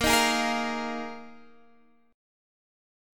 A#7sus4 chord